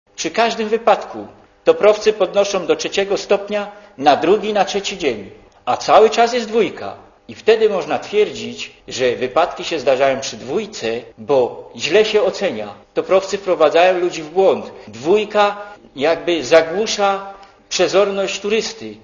Mówi oskarżony nauczyciel (79 KB)